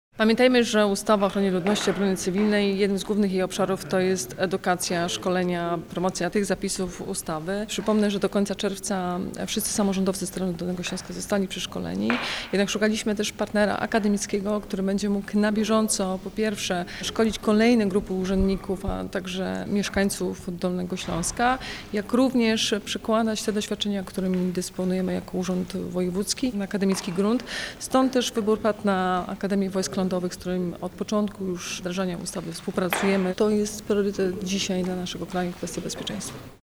O szczegółach mówi Anna Żabska, Wojewoda Dolnośląska.